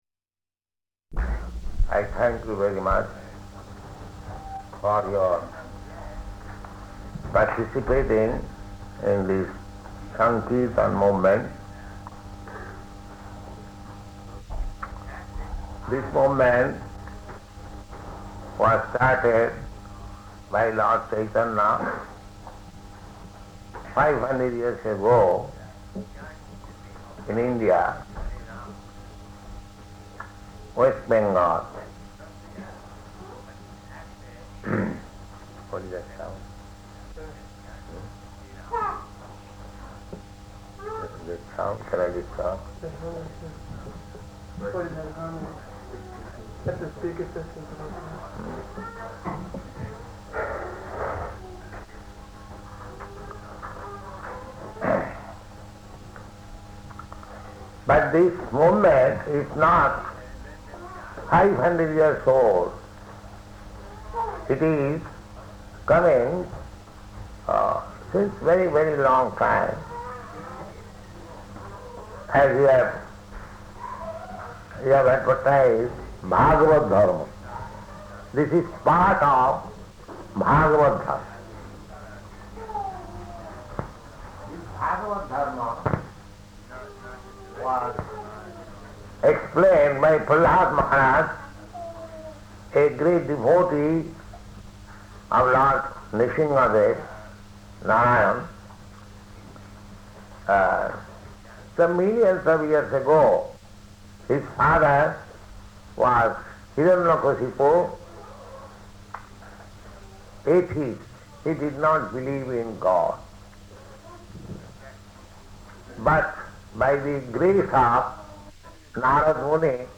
Lecture
Lecture --:-- --:-- Type: Lectures and Addresses Dated: July 12th 1972 Location: London Audio file: 720712LE.LON.mp3 Prabhupāda: I thank you very much for your participating in this saṅkīrtana movement.
[noise of someone speaking in background] [aside:] What is that sound?
[child screaming] [aside:] It is disturbing.